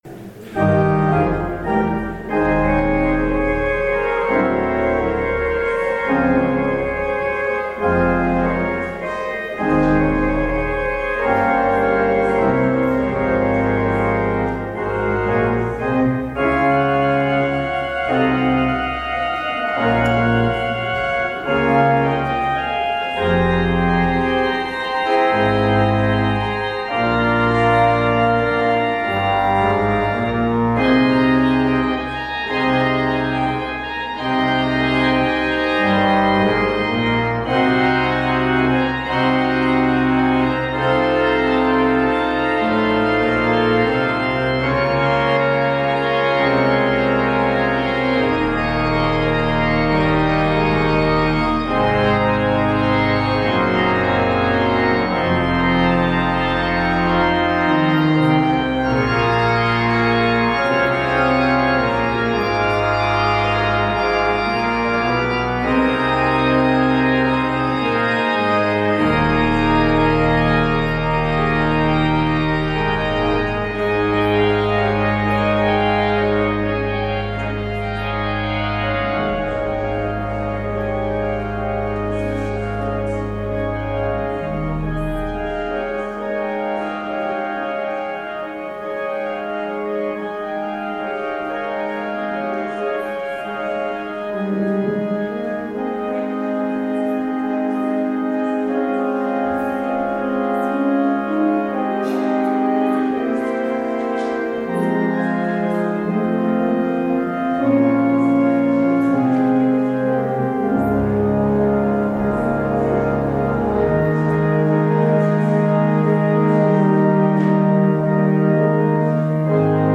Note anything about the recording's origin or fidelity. Audio recording of the 10am service